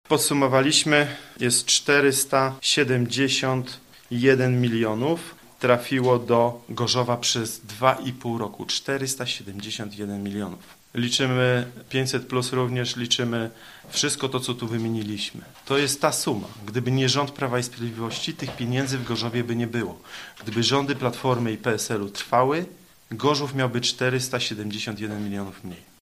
Podczas konferencji prasowej przedstawili listę spraw które udało się zakończyć z sukcesem dla Gorzowa, a wśród nich: powstanie Akademii im. Jakuba z Paradyża, budowa ośrodka TVP w Gorzowie czy duży krok w stronę elektryfikacji linii kolejowej 203.